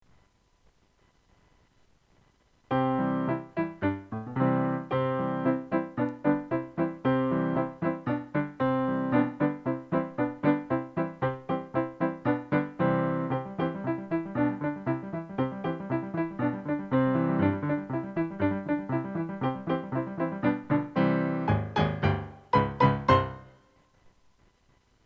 Když jsem přemýšlel na tím, jaké zpívání si pro vás pro tento týden připravím a myslel jsem na to, že jste mi psali, že písničky, které jsem vám nahrál 6.4., jste si zpívali s chutí a dokonce je zpívali i někteří vaši sourozenci a rodiče, rozhodl jsem se, že pro vás nahraji doprovody několika dalších písní.
Přeji vám a případně i vaši sourozencům a rodičům pěkné zpívání a nezapomeňte vždy na vnímání předehry.